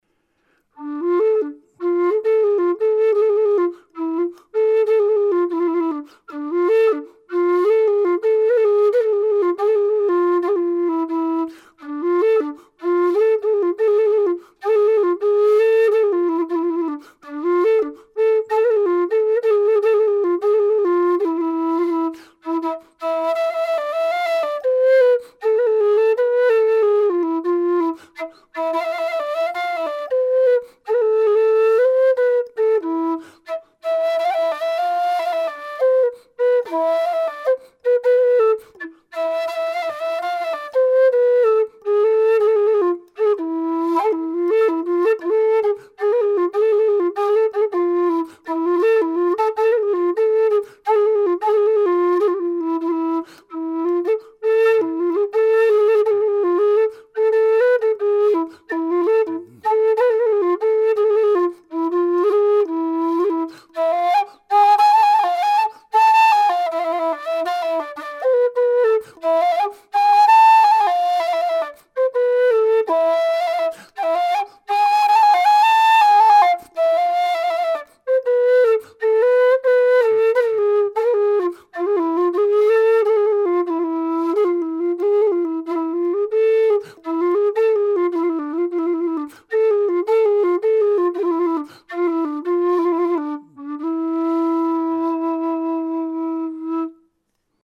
Some sound samples from various Gypsy whistles:
Low D-Gypsy sound sample: (:audioplayer
Note: although the scale patterns are given, as intervals between notes in semi-tones, the Gypsy whistles are not tuned in equal temperament, but for a good sounding intonation, especially for the harmonic and double harmonic scales.
LowDgypsy-impro.mp3